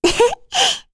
Rehartna-Vox-Laugh1_kr.wav